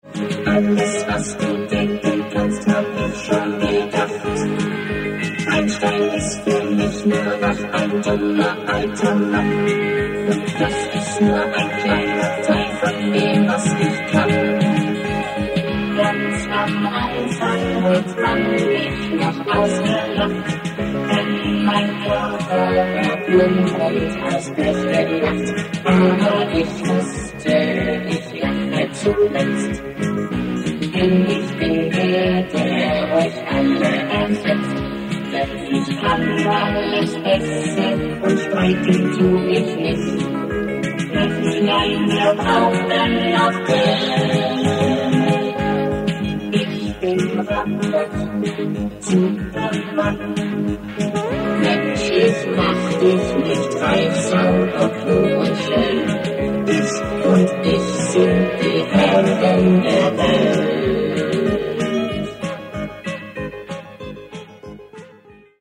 Alle Instrumente und Stimmen: